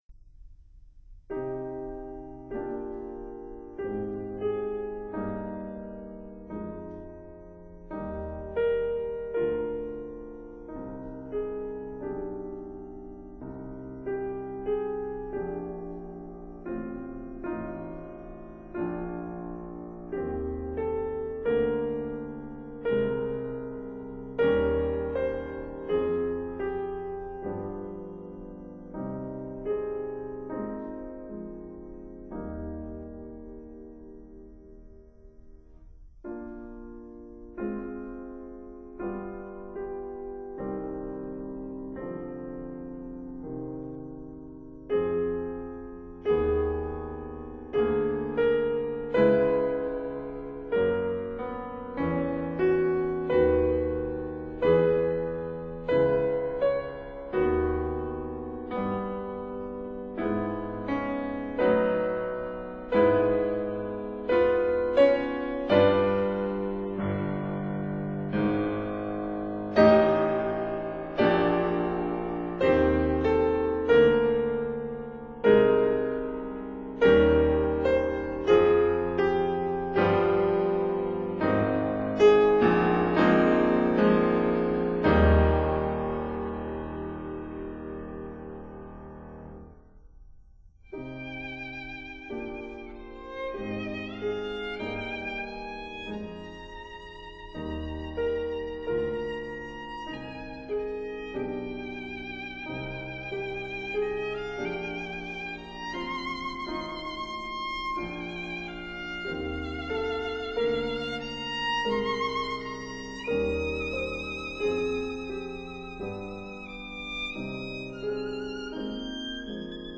•(01) Sonata for cello and piano
•(04) Trio for violin, cello and piano
•(08) Largo, for cello and piano
violin
cello
piano